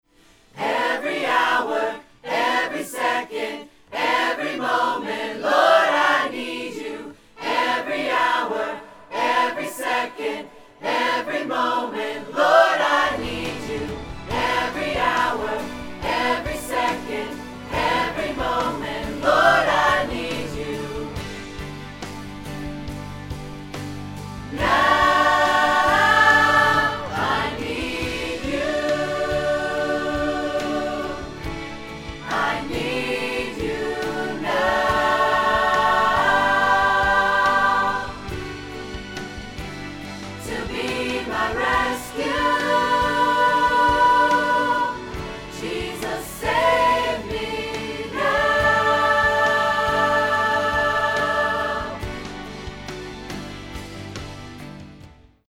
• 0:00 – 0:06 – Choir Mics Soloed
• 0:07 – 0:13 – Choir Mics with Reverb
• 0:13 – 0:55 – Choir Mics with Backing Track
Earthworks FlexWand 730